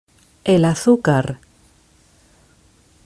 Pronunciation Es El Azúcar (audio/mpeg)